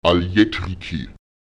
Lautsprecher al’yetrike [alČjEtrike] der Wald